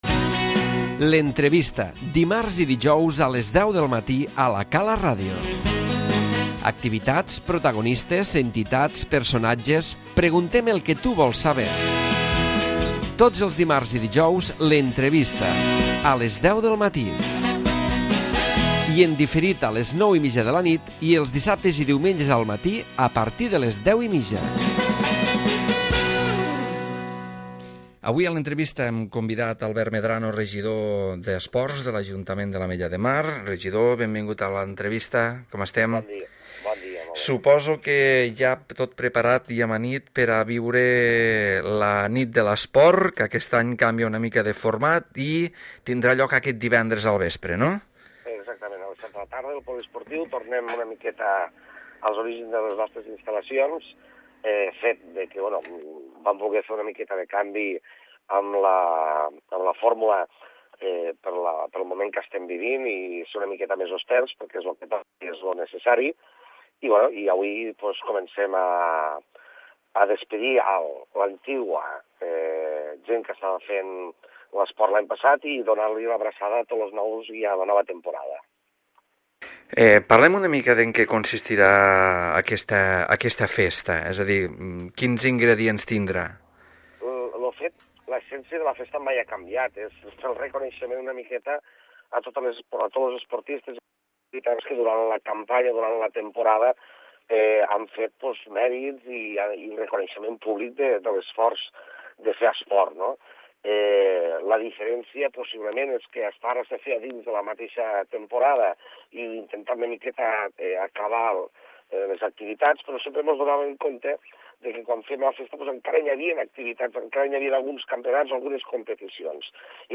La nit de l'Esports, ha estat el tema central de l'entrevista a Albert Medrano, regidor d'Esports de l'Ajuntament de l'Ametlla de Mar.